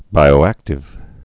(bīō-ăktĭv)